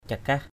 /ca-kah/ (t.) chữ bát. nao takai cakah _n< t=k ckH đi bước chân như chữ bát. cakah-cakah ckH-ckH chân chữ bát. yam nao cakah-cakah y’ _n< ckH-ckH đi hàng hai;...
cakah.mp3